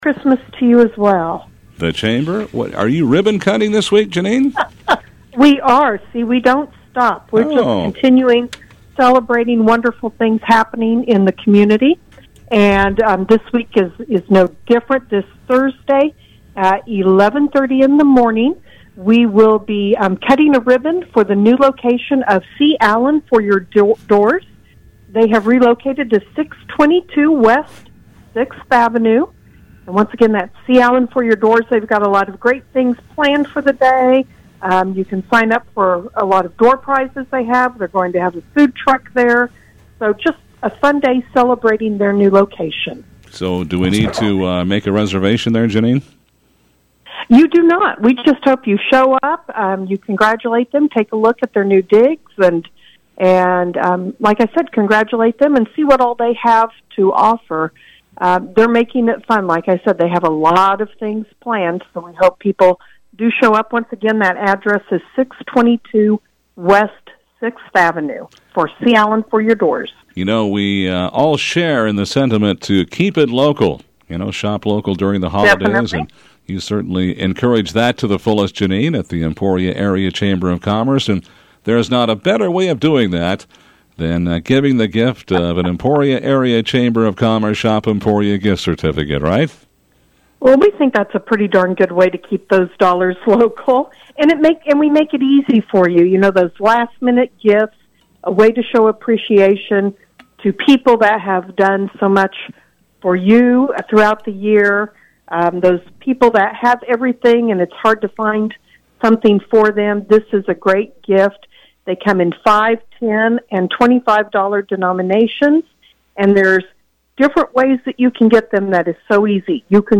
Live Call-In: Emporia Chamber of Commerce